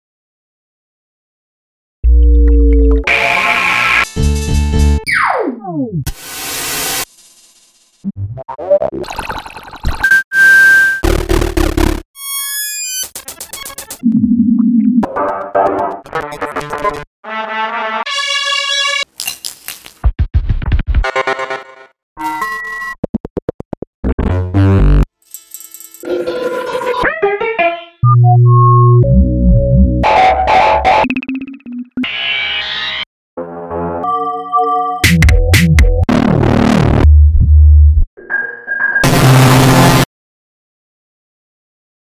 And since I’m a shameless glory hog, I did a collection of 38 1-second songs a few months ago as a tribute to the CDM and Music Thing things, you can check it out on the SIGHUP website.
Listen to the MP3 and marvel how, yet again, there’s really quite a range of what can squeeze into a second.